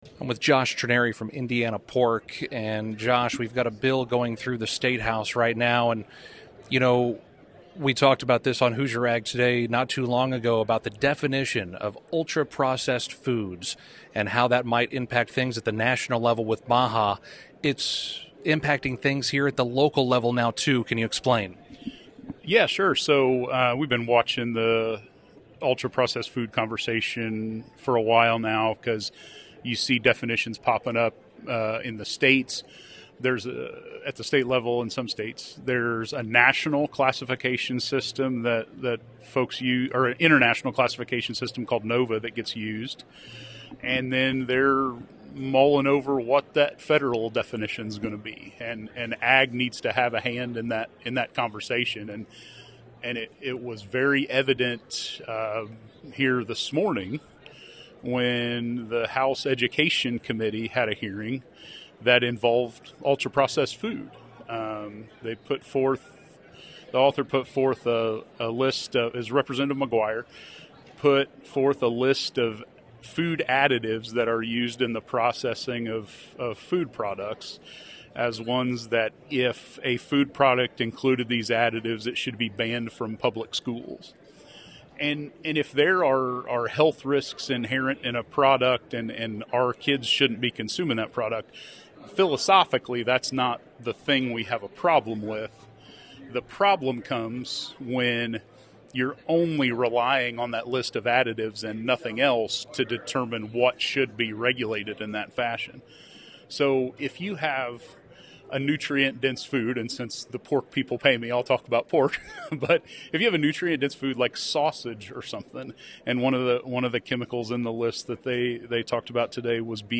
full HAT interview